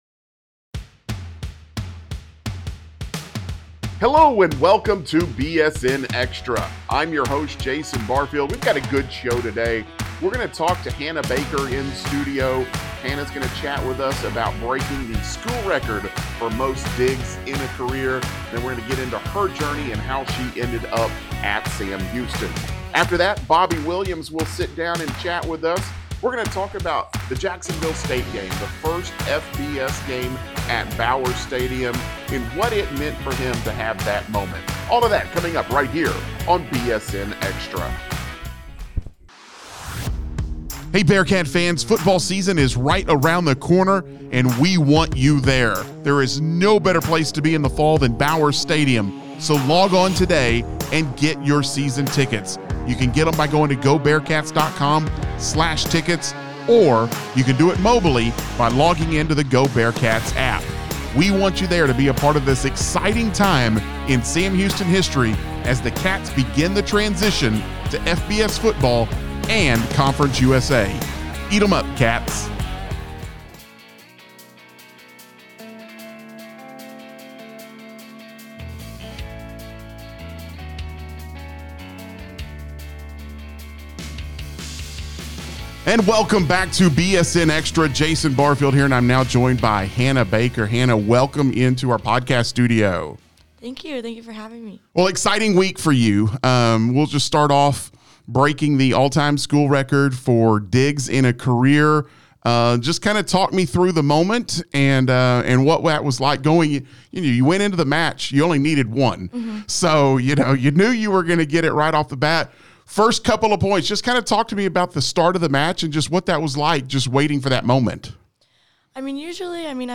joins in studio to talk about breaking the school record for most digs in a career.